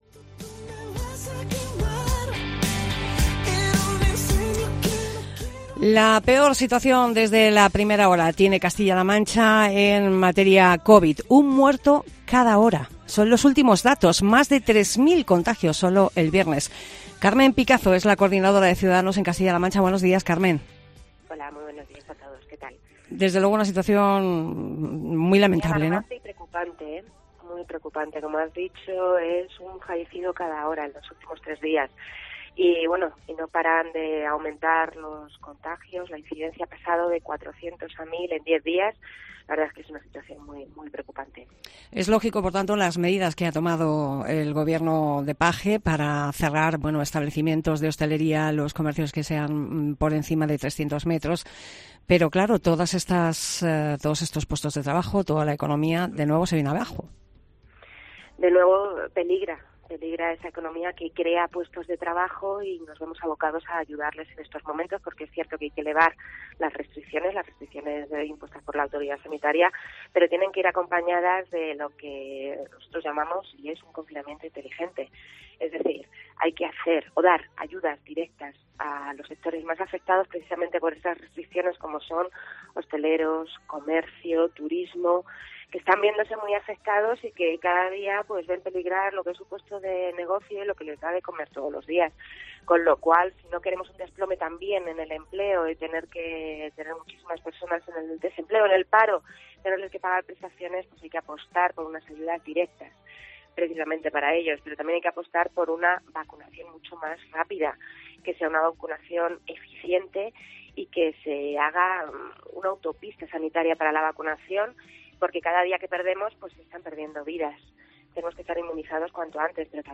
Entrevista a Carmen Picazo coordinadora de Cs CLM